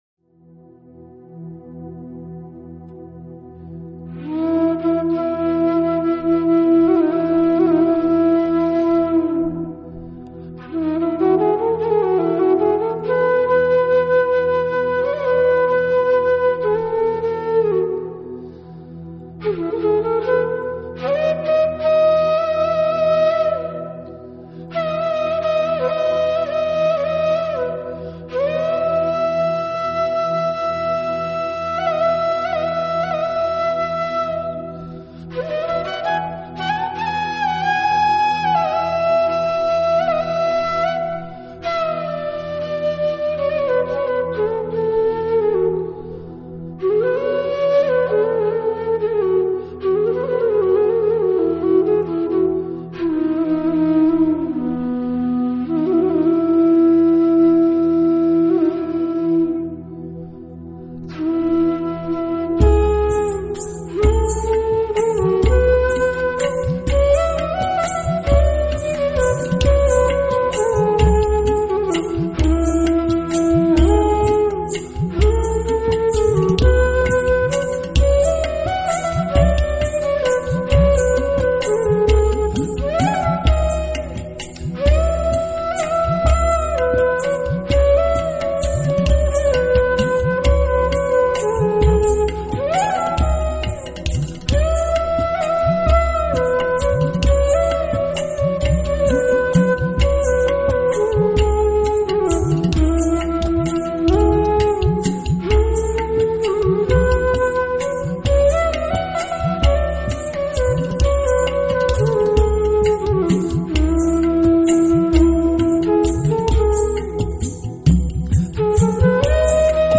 所以低音班苏里的音色类似洞箫。